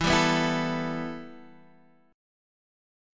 E Minor Add 11th